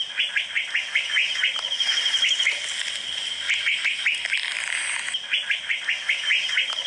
Pheugopedius mystacalis
Nome em Inglês: Whiskered Wren
País: Colômbia
Localidade ou área protegida: Ibague Zona De Boqueron
Condição: Selvagem
Certeza: Gravado Vocal